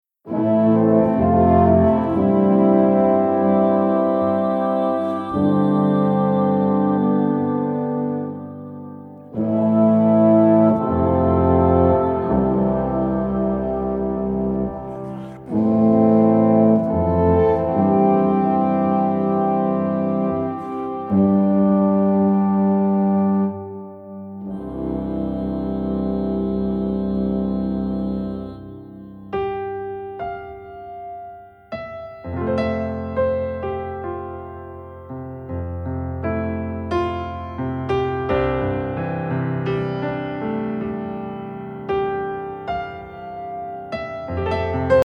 Mp3 Instrumental Song Download